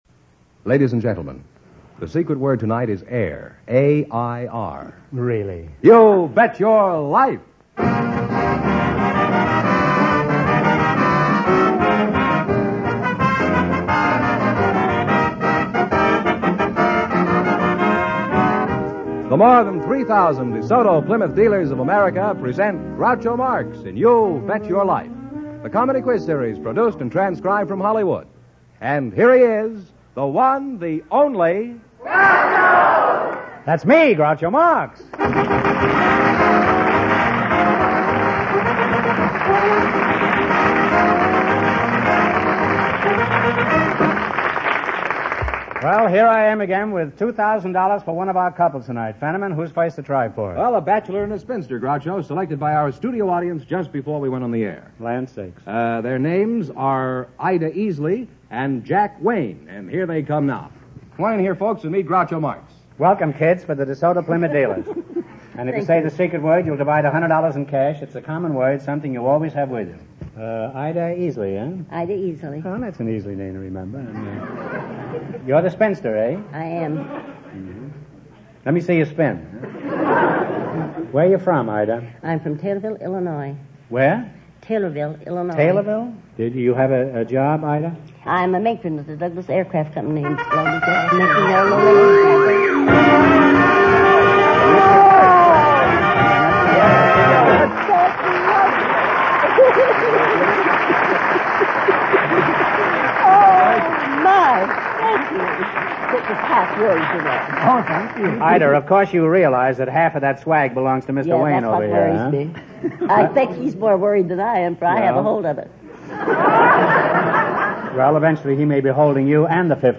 You Bet Your Life Radio Program, Starring Groucho Marx